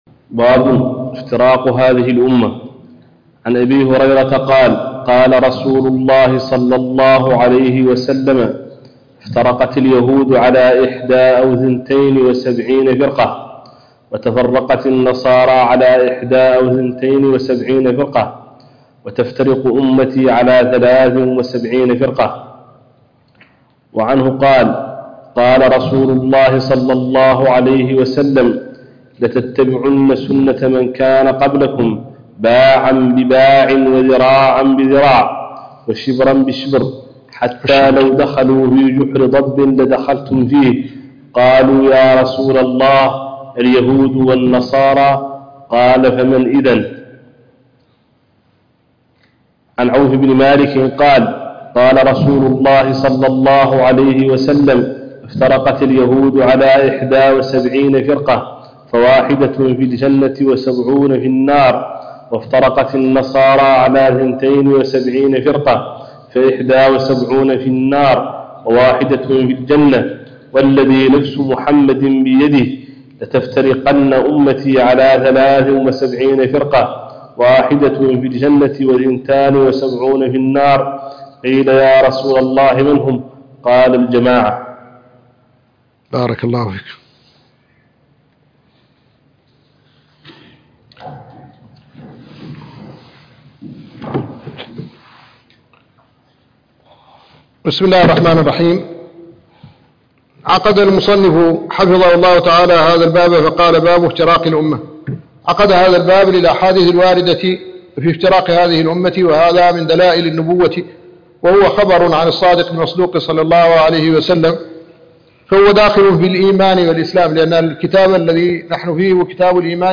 الدرس الأربعون من شرح وتعليق الشيخ الددو على كتاب معالم السنة النبوية - الشيخ محمد الحسن ولد الددو الشنقيطي